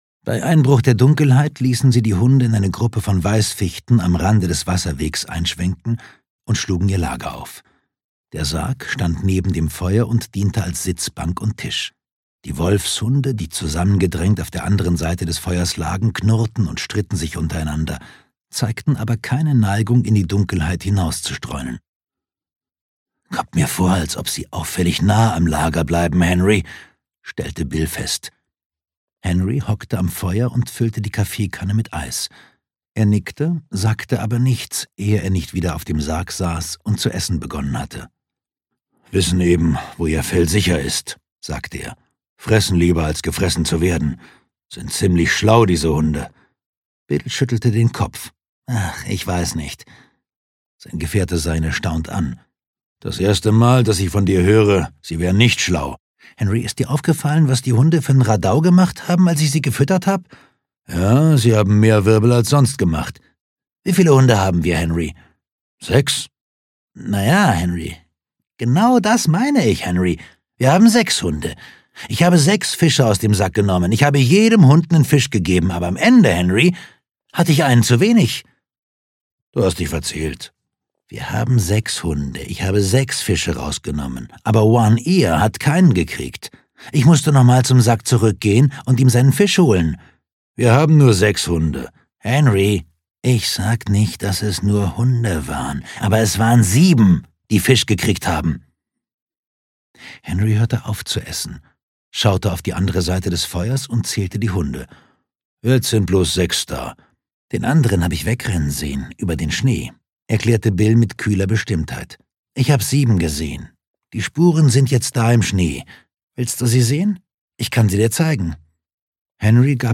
Wolfsblut (DE) audiokniha
Audio kniha
Ukázka z knihy